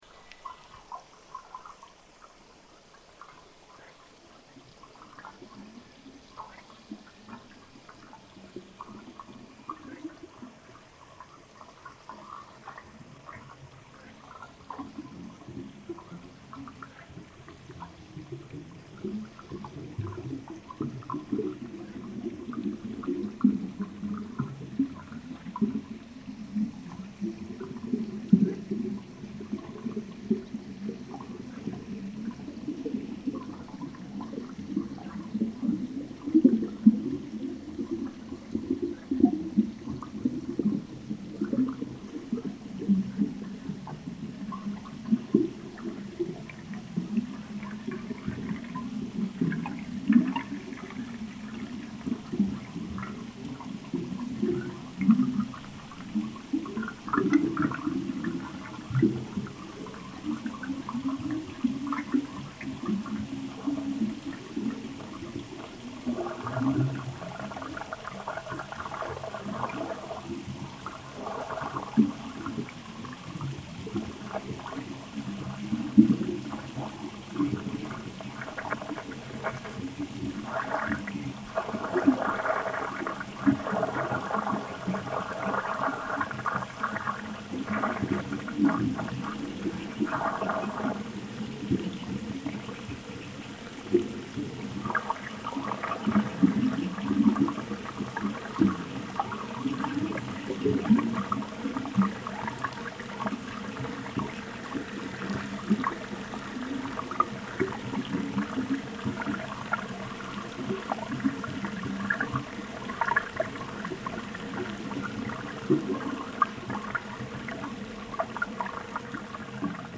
Soundscape Series